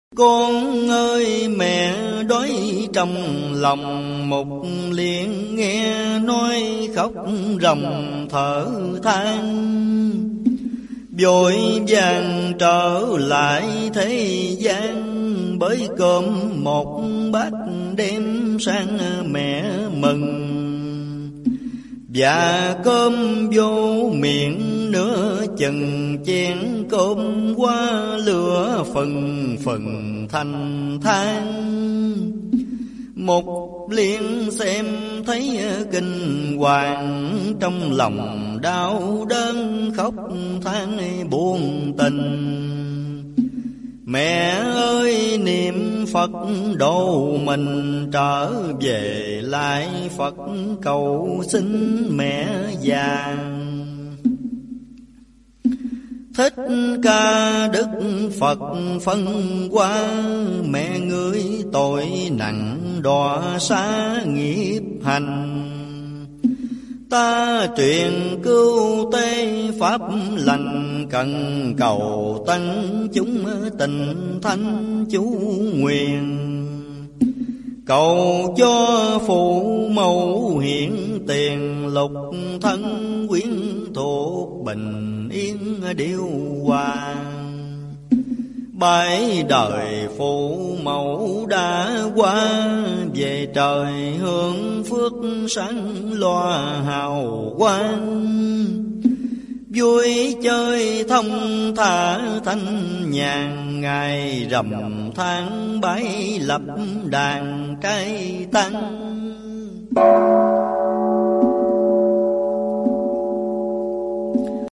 Giọng tụng